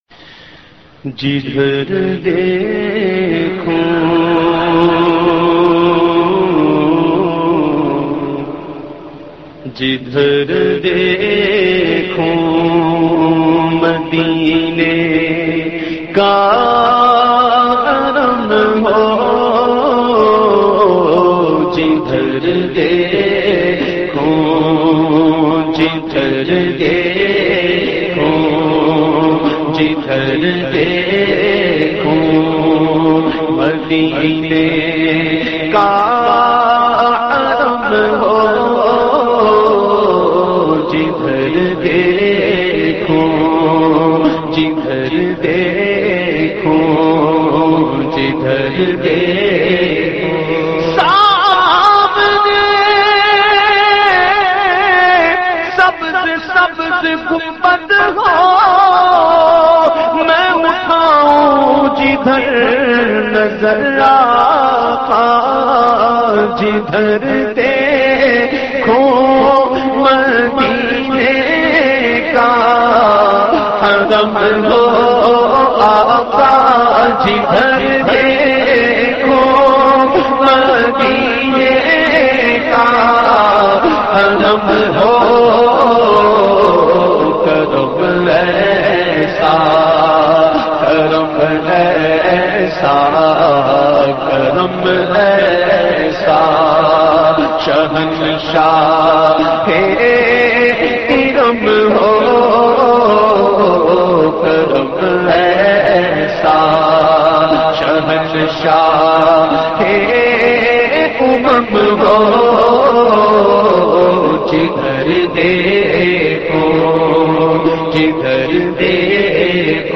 The Naat Sharif jidhar dekhoon madinay ka haram ho recited by famous Naat Khawan of Pakistan Owais Raza Qadri.